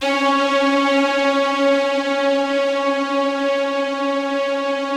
BIGORK.C#3-L.wav